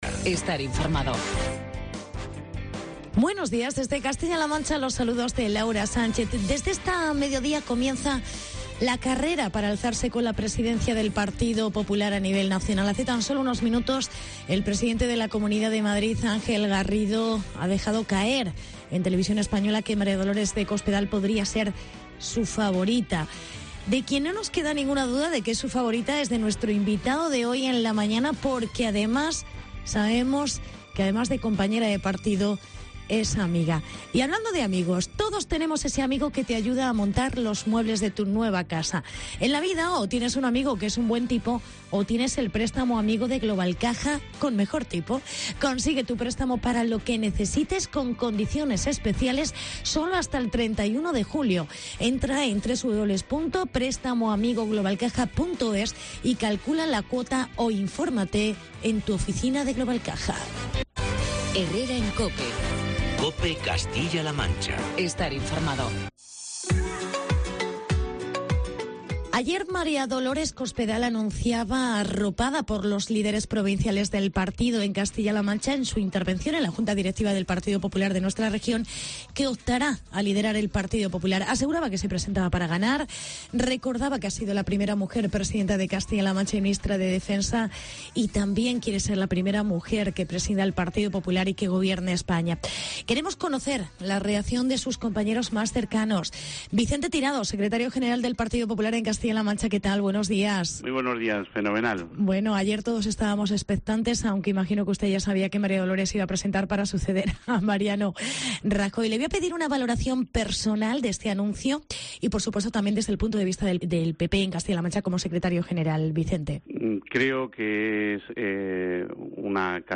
AUDIO: Entrevista con Vicente Tirado tras el anuncio de Cospedal como candidata a suceder a Mariano Rajoy